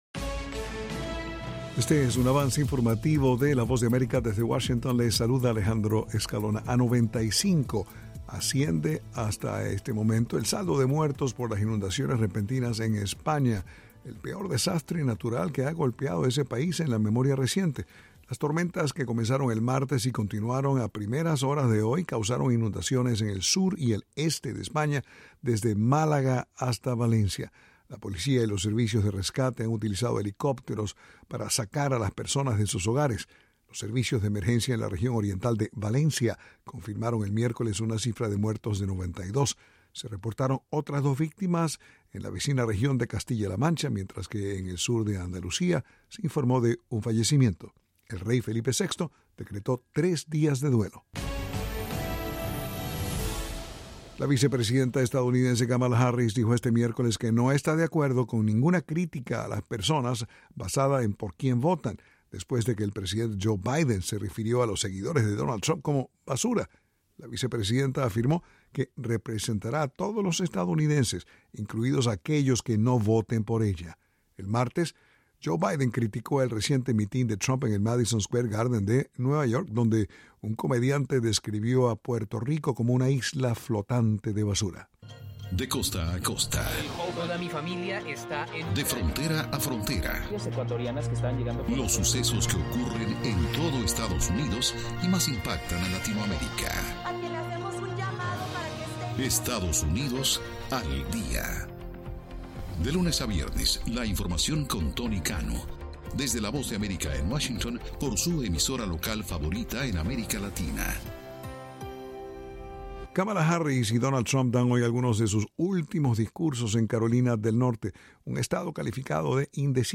El siguiente es un avance informativo presentado por la Voz de América en Washington.